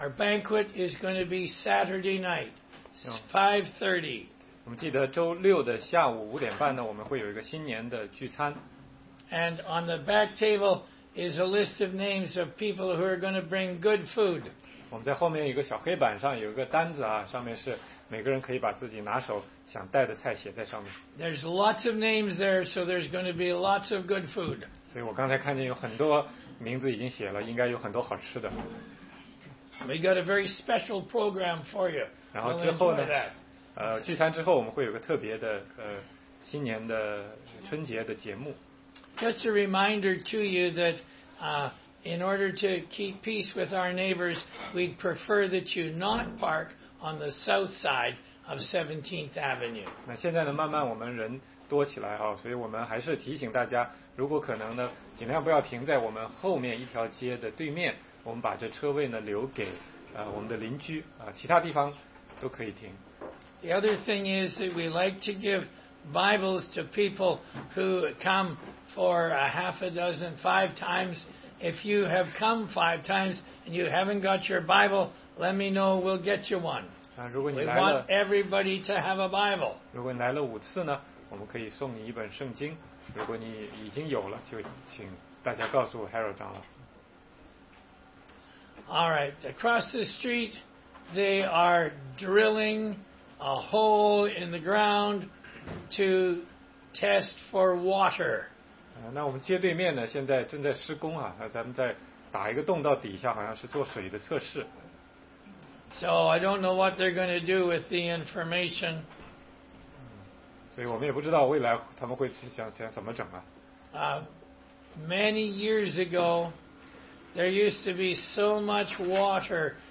16街讲道录音 - 罗马书3章7节-31节